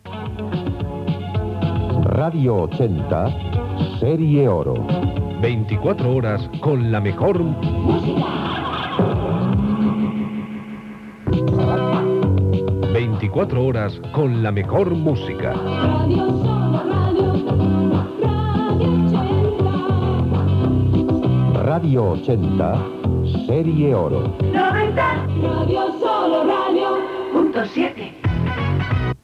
Dos indicatius de l'emissora
FM